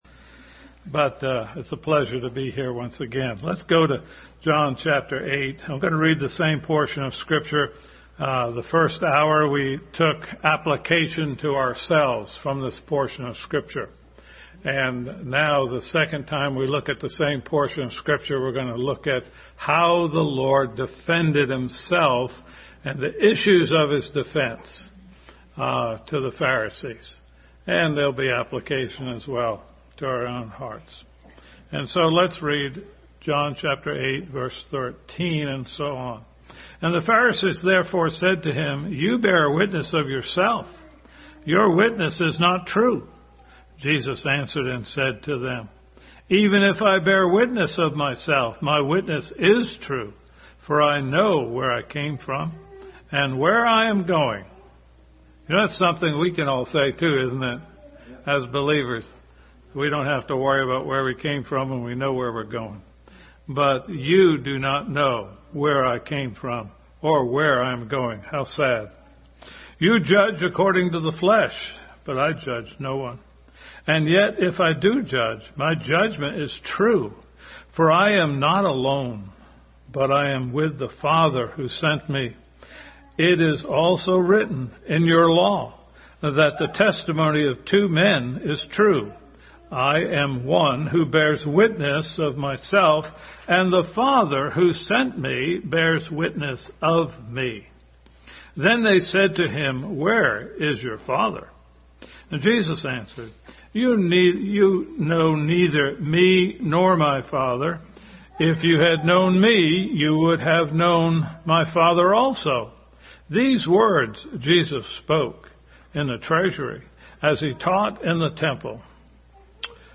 Passage: John 8:13-20, Deuteronomy 17:6, 19:16-19, 1 Timothy 5:19, John 14:6, 1:14, 16:16, 5:31, 1 John 5:7-8 Service Type: Family Bible Hour